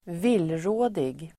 Uttal: [²v'il:rå:dig]